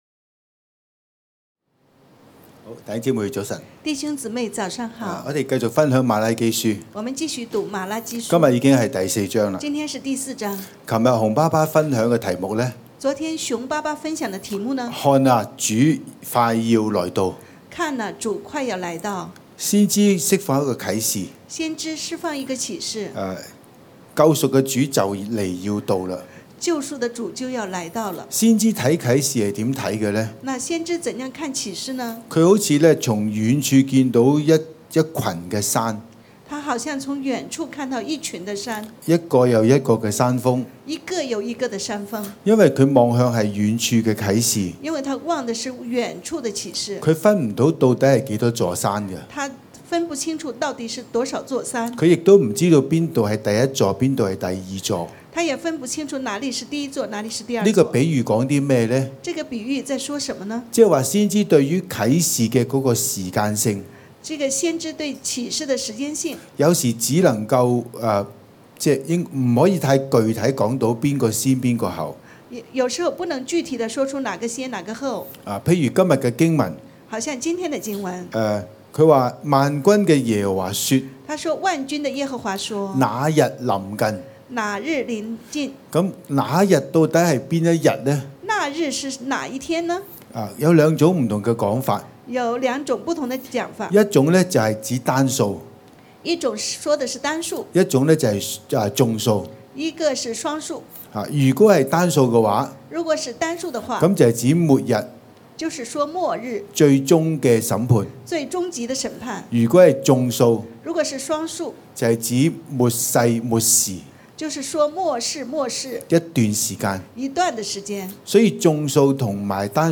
弟兄姊妹跟著到台前分享：感恩有神話語的餵養，在組長的牧養下，生命逐漸改變，能夠與父親復和，與人相處恢復有溫度，不再壓制情緒，願意敞開自己和服事神！